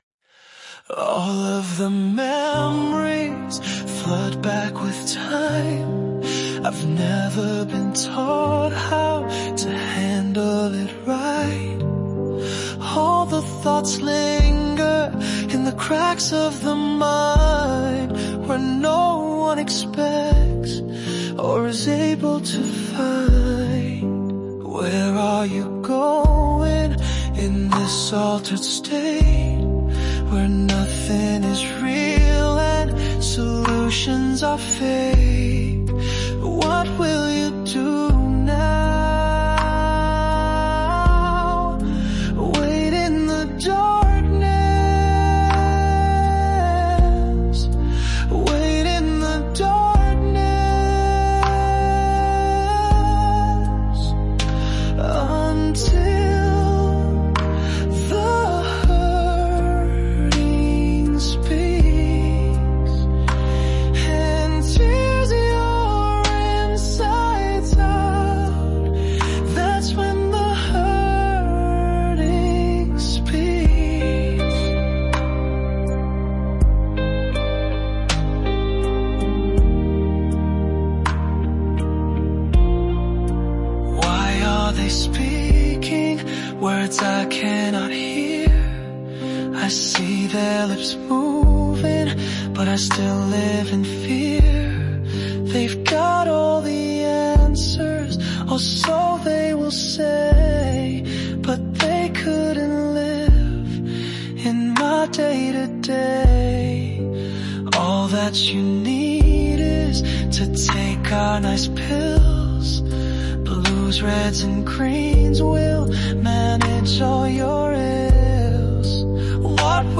Song about dealing with mental illness.